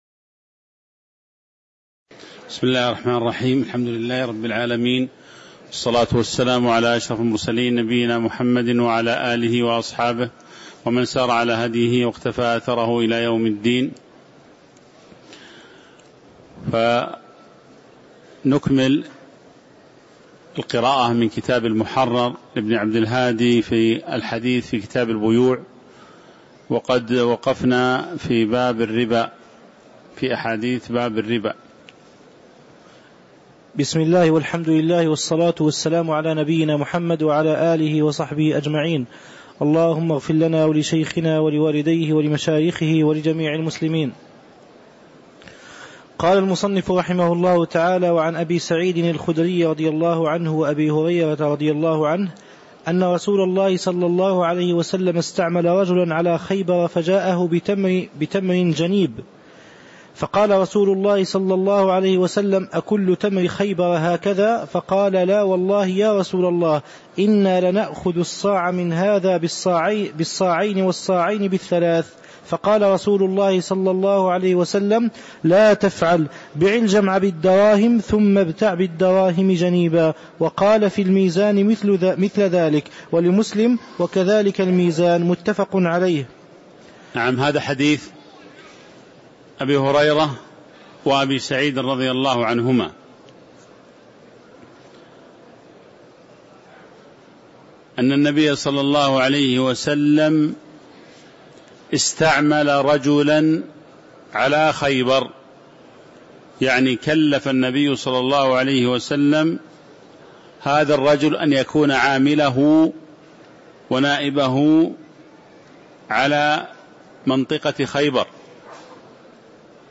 تاريخ النشر ٦ جمادى الآخرة ١٤٤٦ هـ المكان: المسجد النبوي الشيخ